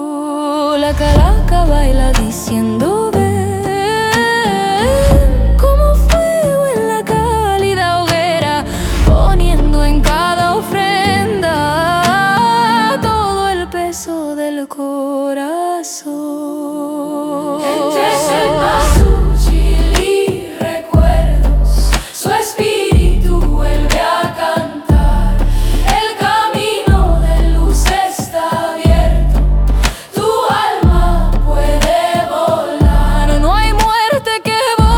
Жанр: Альтернатива